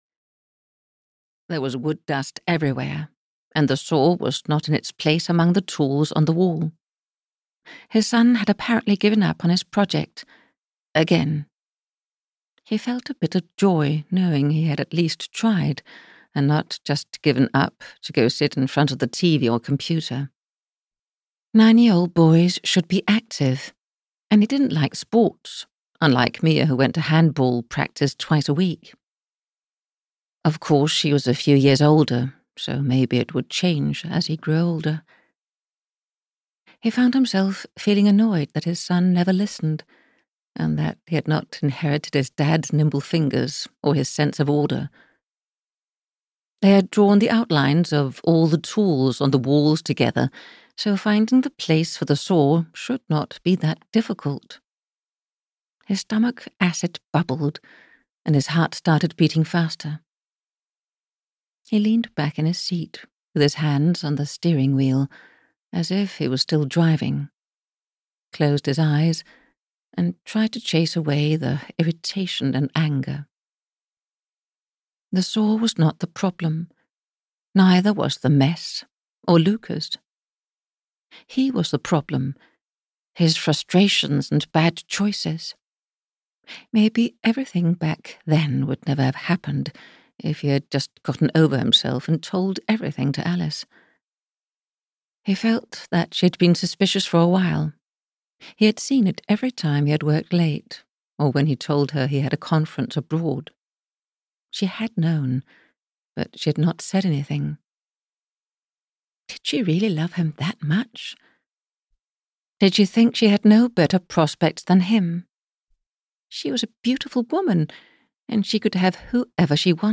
Burning Guilt - Chapter 1 (EN) audiokniha
Ukázka z knihy